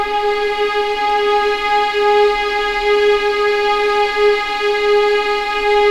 G#4LEGPVLN L.wav